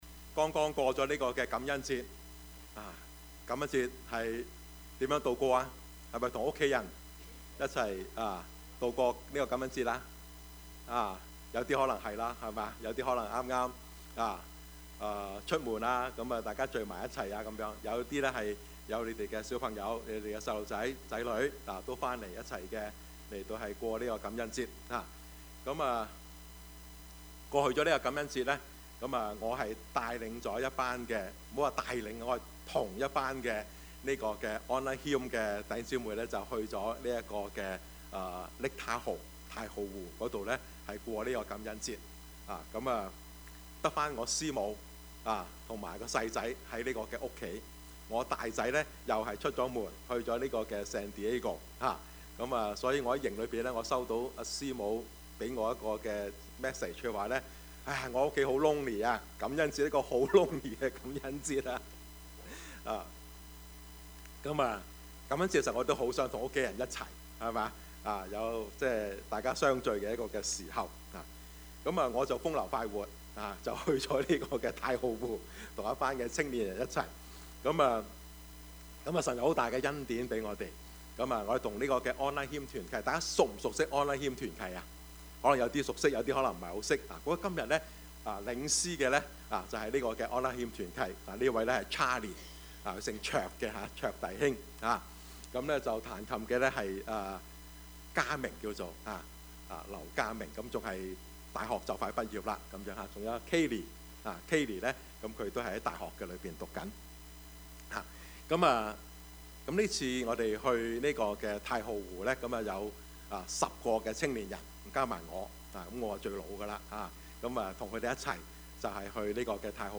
Service Type: 主日崇拜
Topics: 主日證道 « 從敬畏到感恩，從感恩到喜樂 識時務者為俊傑 »